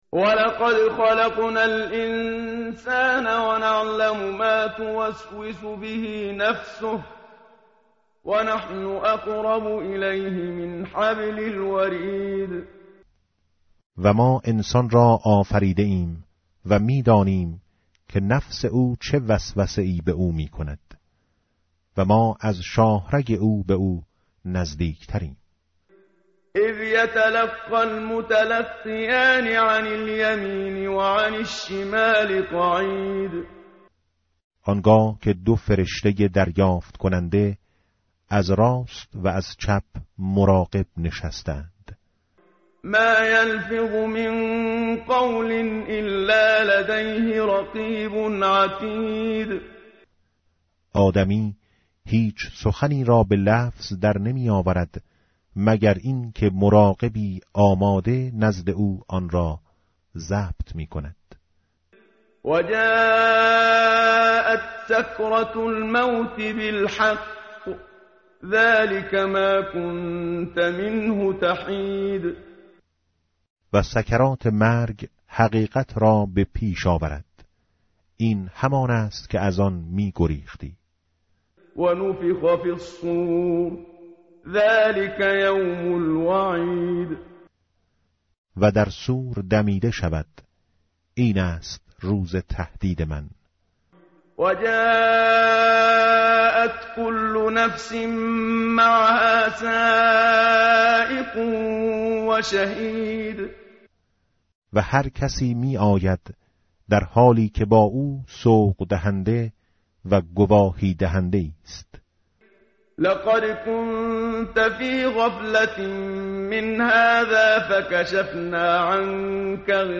tartil_menshavi va tarjome_Page_519.mp3